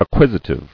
[ac·quis·i·tive]